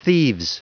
Prononciation du mot thieves en anglais (fichier audio)
Prononciation du mot : thieves